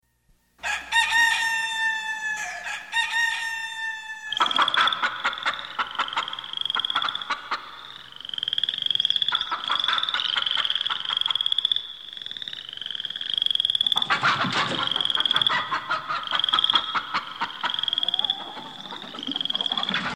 دانلود آهنگ سه بعدی 8 از افکت صوتی طبیعت و محیط
دانلود صدای سه بعدی 8 از ساعد نیوز با لینک مستقیم و کیفیت بالا
جلوه های صوتی